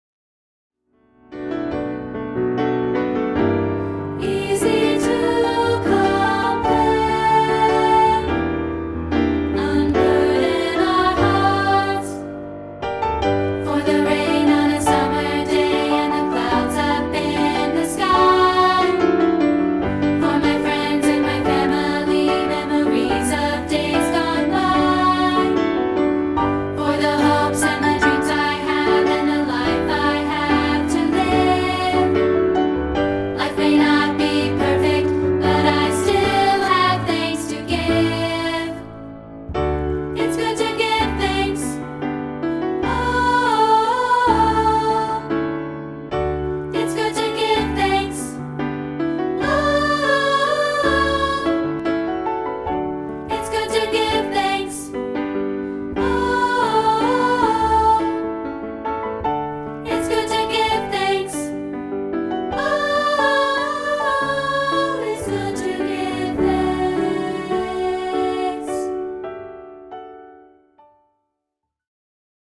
Here's a free rehearsal track of part 2, isolated,